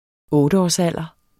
Udtale [ ˈɔːdəɒs- ]